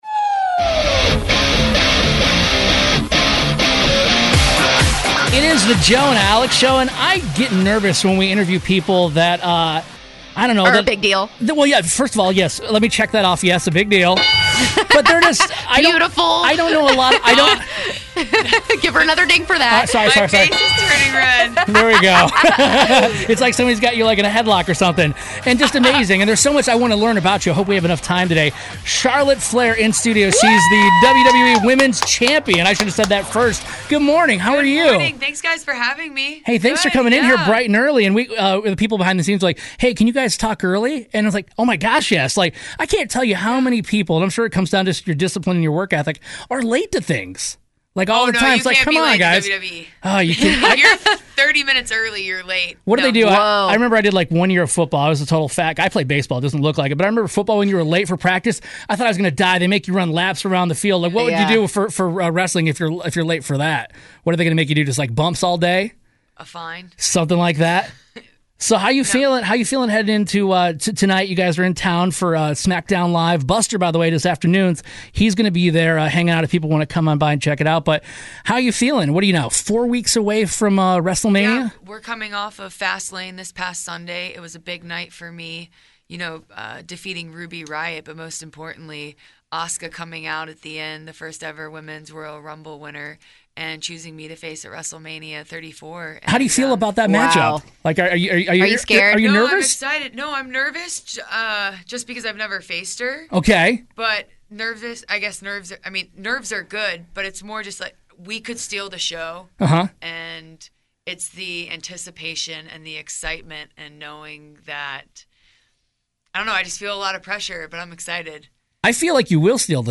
Charlotte Flair In Studio - Part One